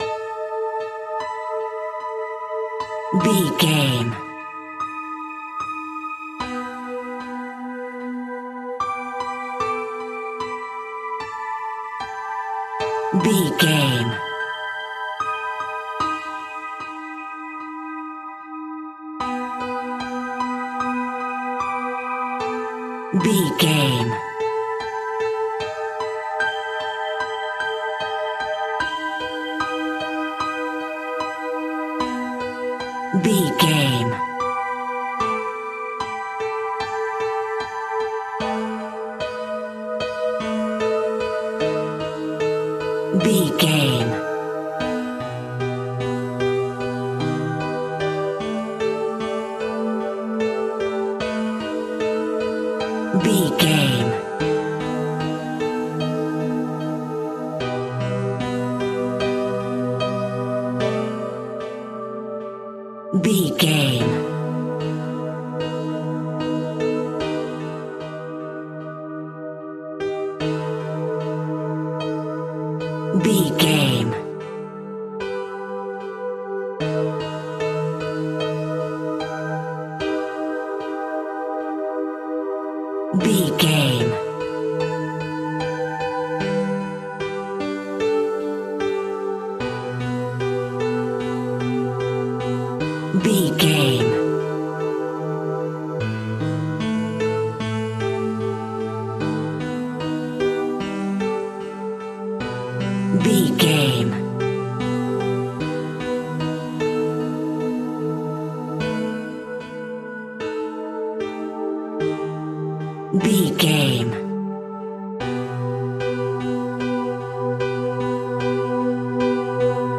Aeolian/Minor
tension
ominous
dark
suspense
haunting
eerie
synthesiser
electric piano
Acoustic Piano